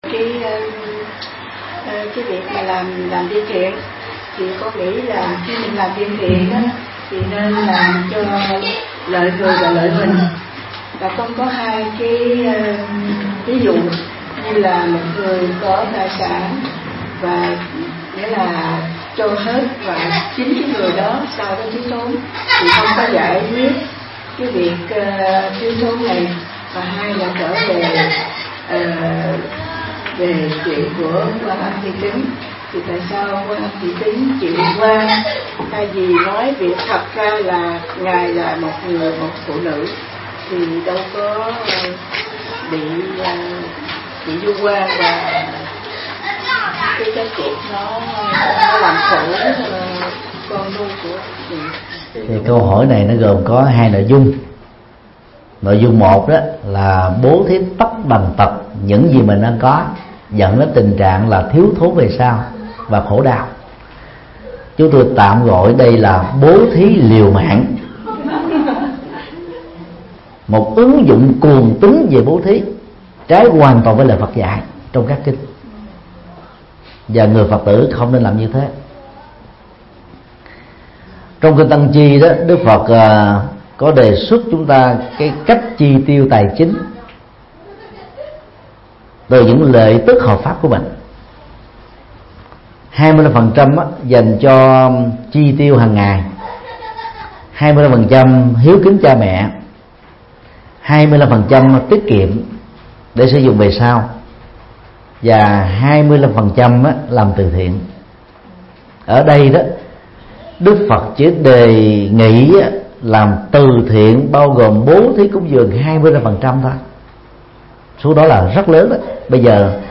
Mp3 Vấn đáp: Quan Âm Thị Kính và nỗi hàm oan
Giảng tai chùa Linh Phong,Thụy Sĩ,ngày 5 tháng 7 năm 2015